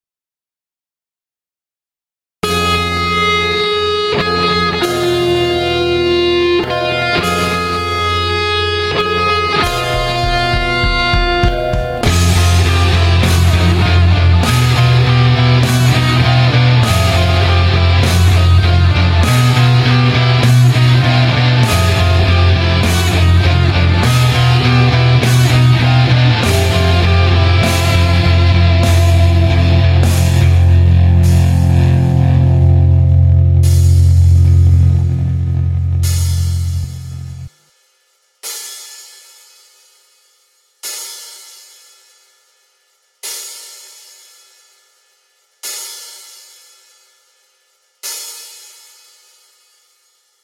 Вниз  Играем на гитаре
DoomMusic.mp3